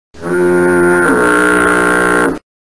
Spongebob Gross Sound - Bouton d'effet sonore